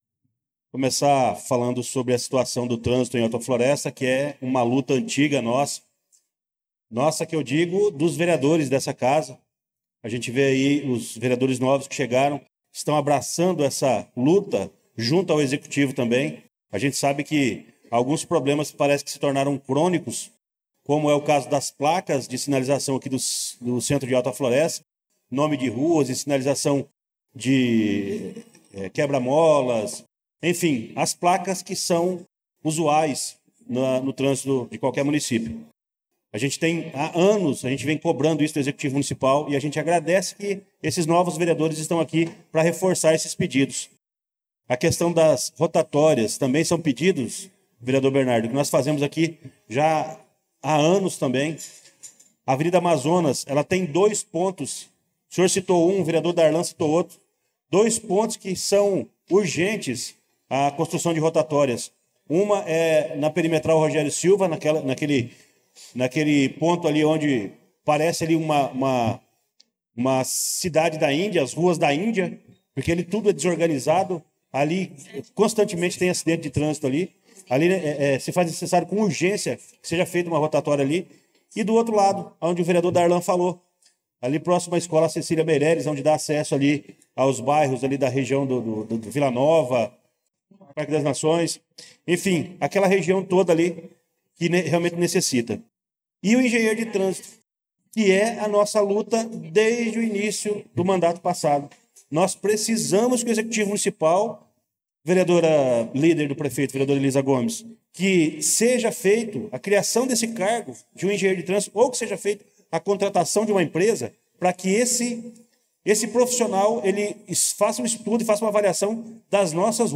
Pronunciamento do vereador Luciano Silva na Sessão Ordinária do dia 11/02/2025